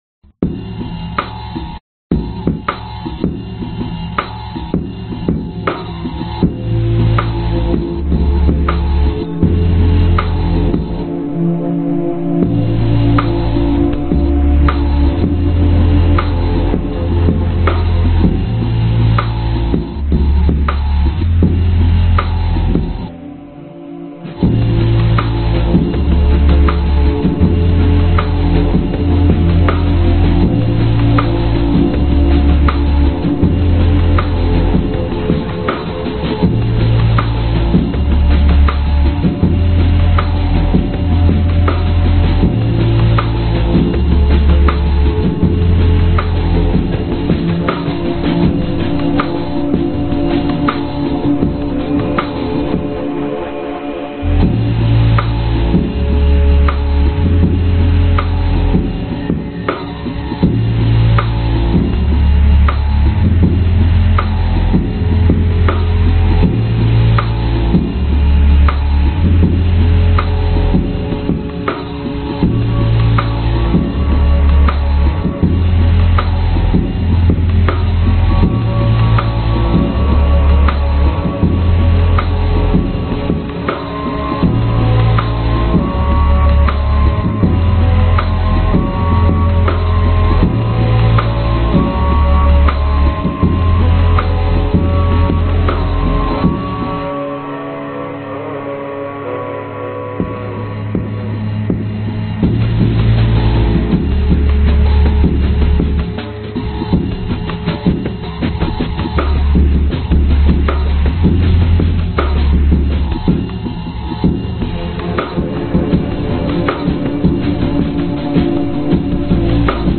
周围环境摘录
Tag: 128 bpm House Loops Synth Loops 969.71 KB wav Key : Unknown